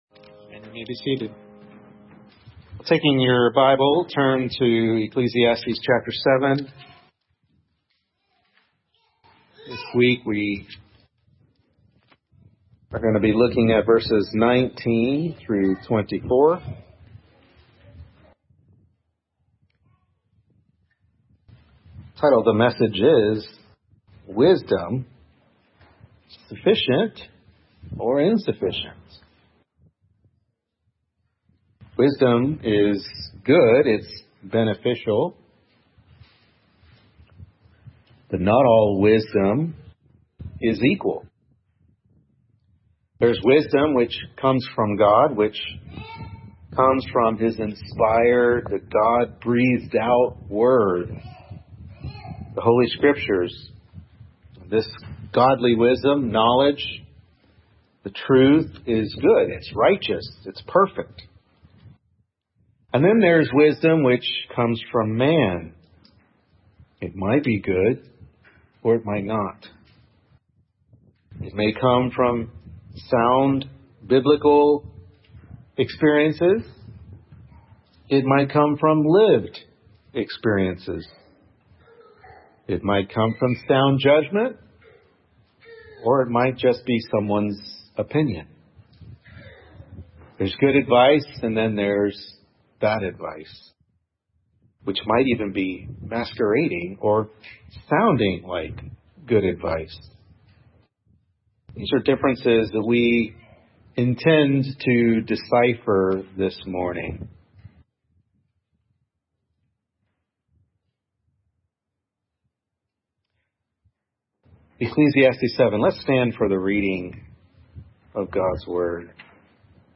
Ecclesiastes 7:19-24 Service Type: Morning Worship Service Ecclesiastes 7:19-24 Wisdom